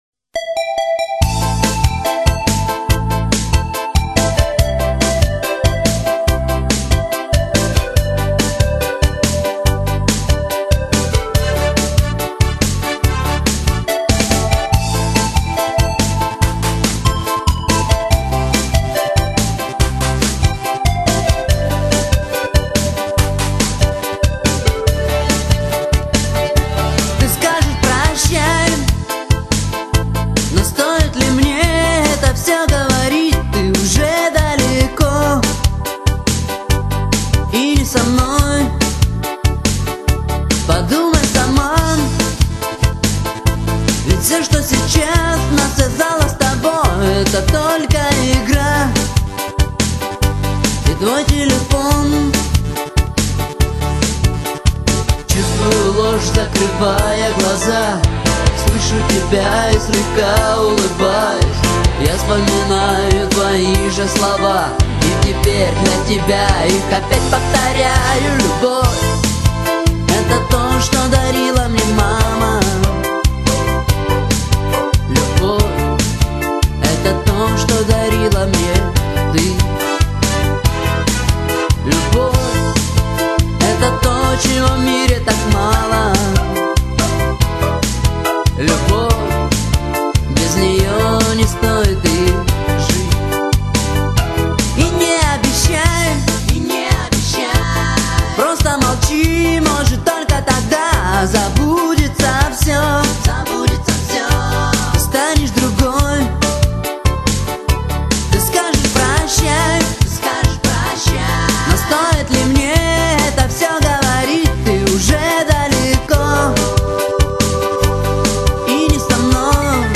LIVE 2011г